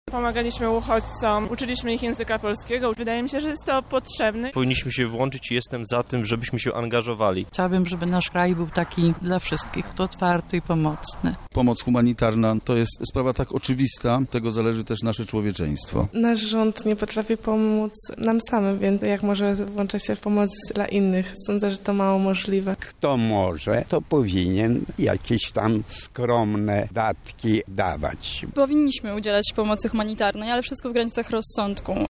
Sprawdziliśmy, co o pomocy humanitarnej sądzą mieszkańcy Lublina: